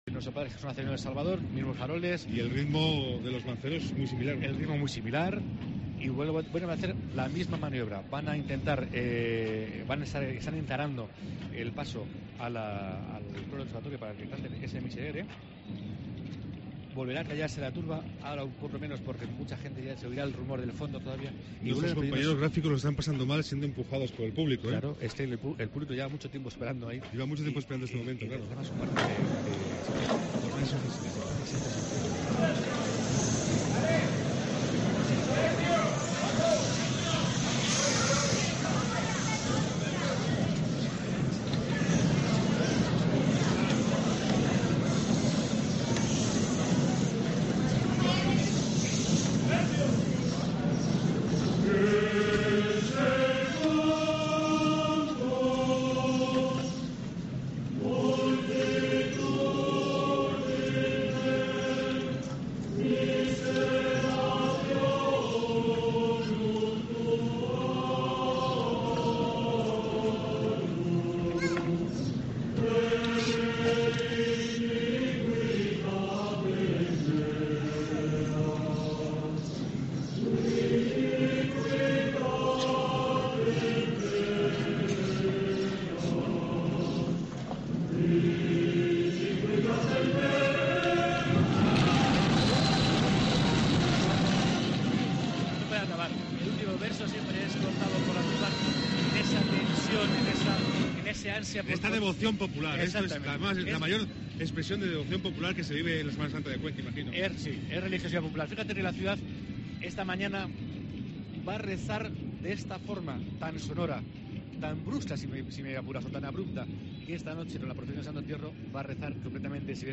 AUDIO: La Voz de la Pasión retransmisión nacional del Camino del Calvario y Miserere de 11.30 a 11.45 horas